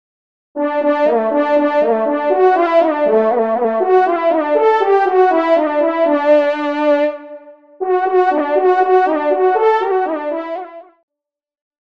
Genre :  Fanfare de circonstances
Extrait de l’audio-pédagogique  (Tester)